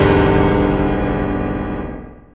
効果音
紙を破る音、ゴクゴク飲む音、トイレの音など・・・、ありそうでなさそな音40点を集めたゲーム用効果音素材集！